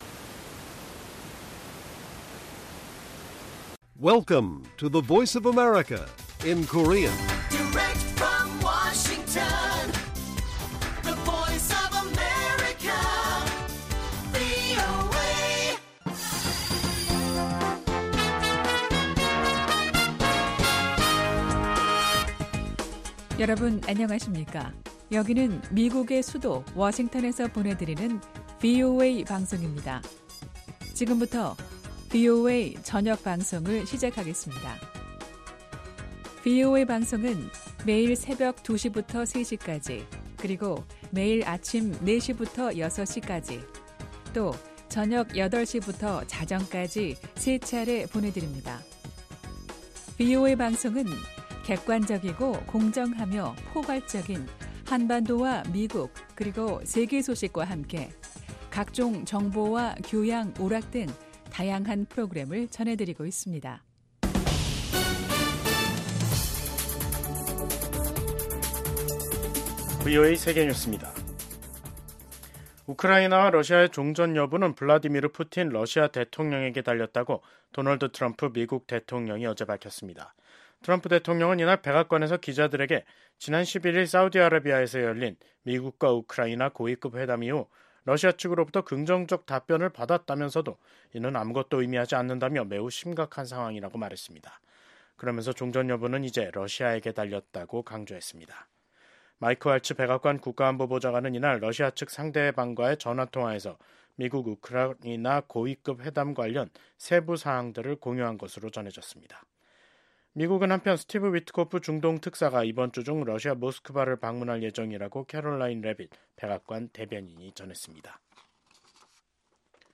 VOA 한국어 간판 뉴스 프로그램 '뉴스 투데이', 2025년 3월 13일 1부 방송입니다. 도널드 트럼프 대통령이 한반도를 포함한 역내 문제를 담당하는 국무부 동아태 치관보에 마이클 디섬브레 전 태국 대사를 지명했습니다. 한국 정치권에서 핵잠재력 확보 주장이 제기된 가운데 국무부가 동맹에 대한 미국의 방위 공약을 거듭 강조했습니다. 미국 해병대는 한반도 위기 발생 시 방어를 지원할 준비가 돼 있다고 크리스토퍼 마호니 해병대 부사령관이 밝혔습니다.